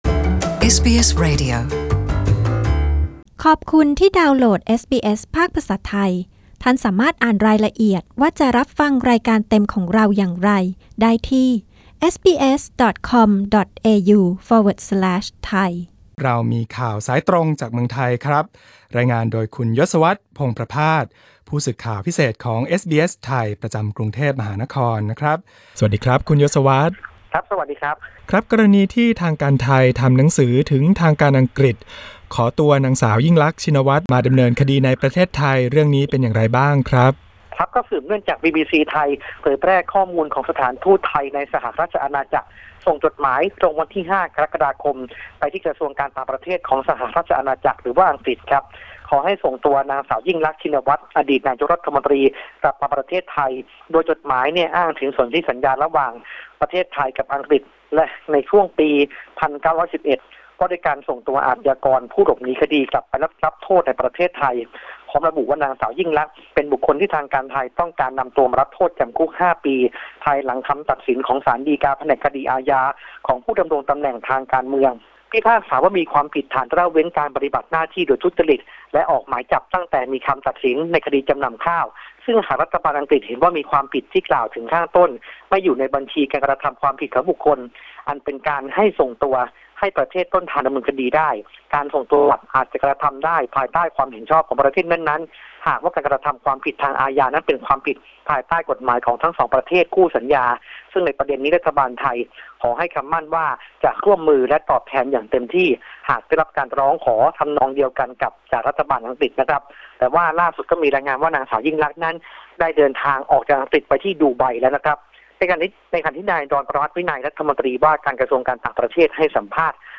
Thai phone-in news 2 AUG 2018